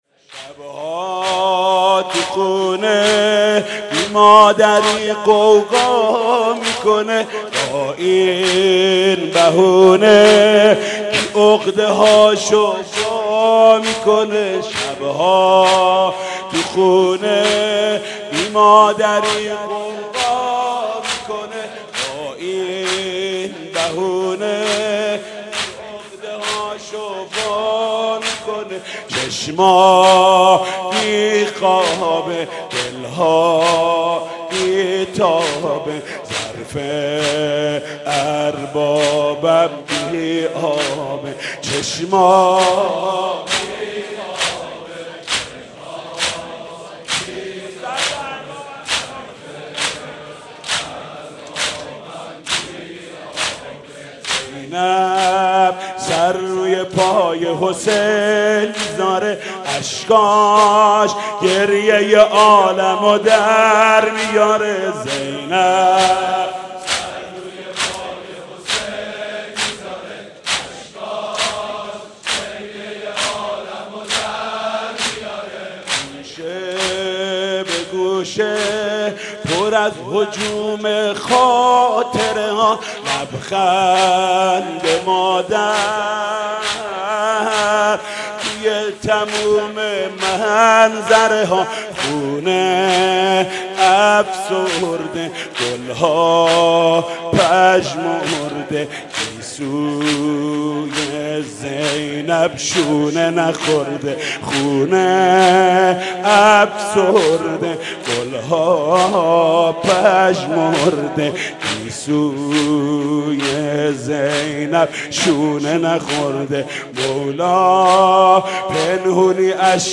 «فاطمیه 1392» زمینه: شب ها تو خونه بی مادری غوغا می کنه